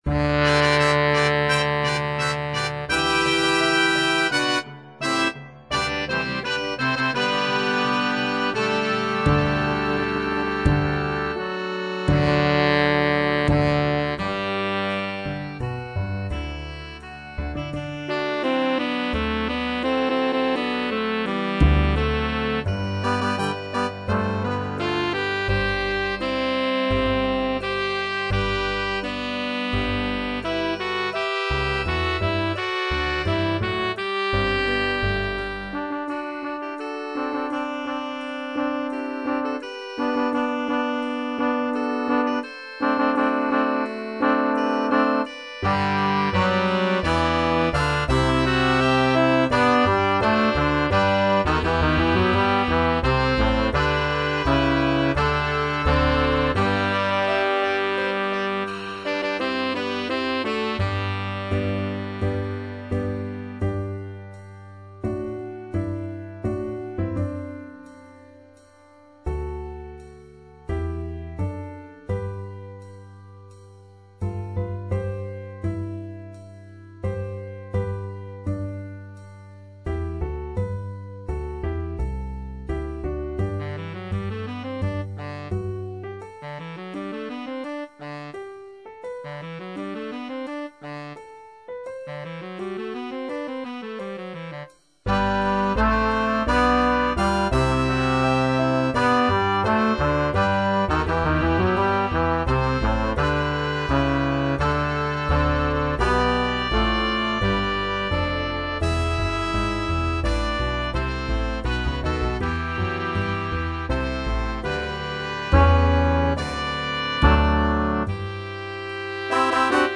Style: Traditional
Instrumentation: Jazz Band + Chorus or Soloist